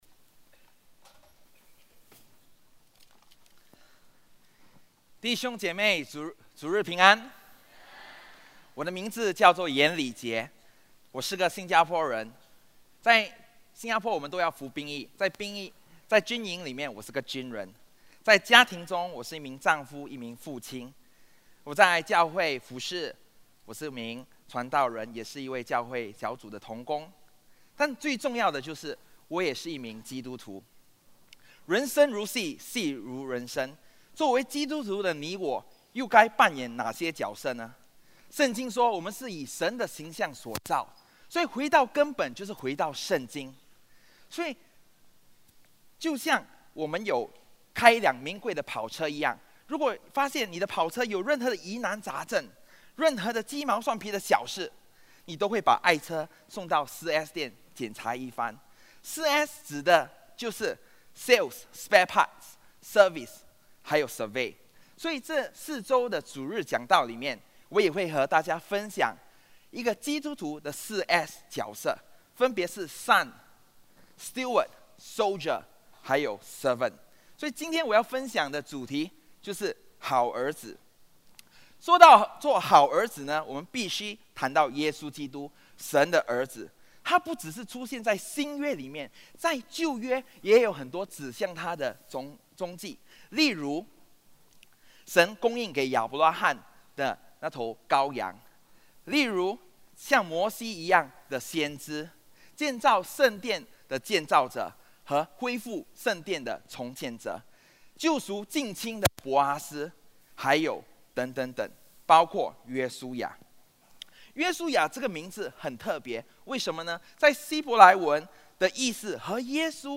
主日证道 | 基督徒系列一：好儿子